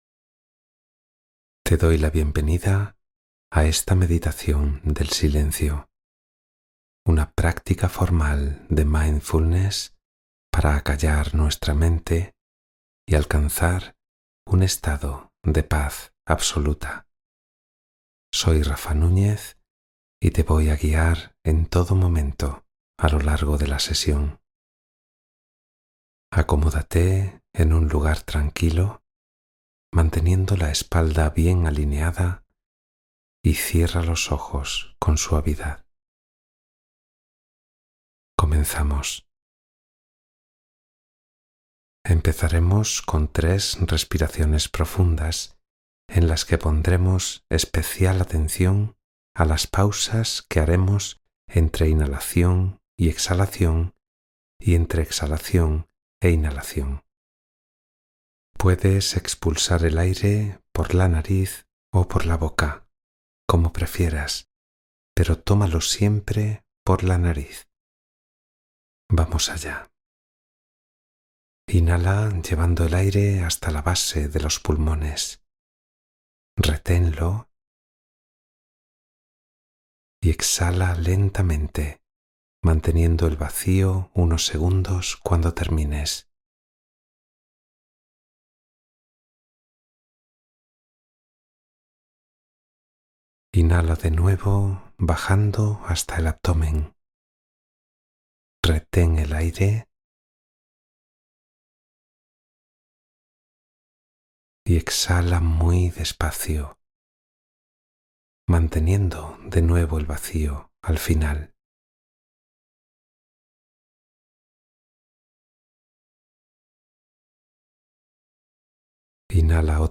Meditación mindfulness para calmar la mente: la meditación del silencio